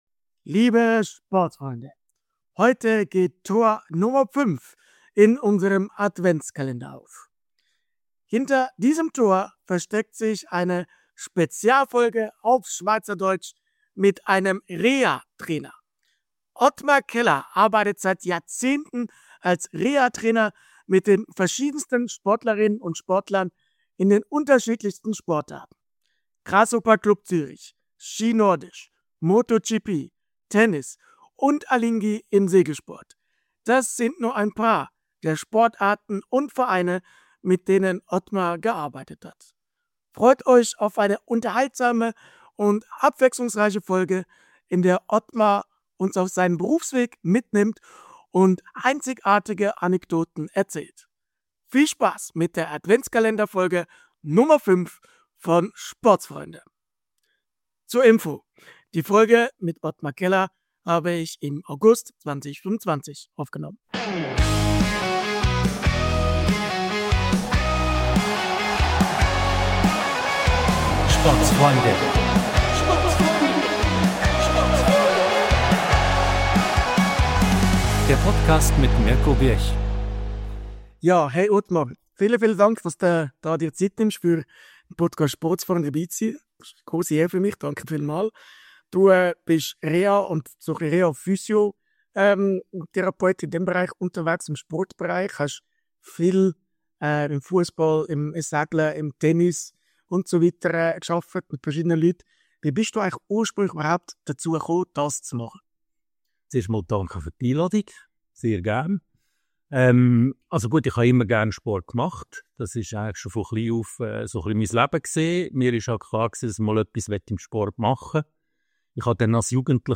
Spezialfolge auf Schweizerdeutsch! ~ Mixed-Sport Podcast